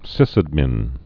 (sĭsəd-mĭn)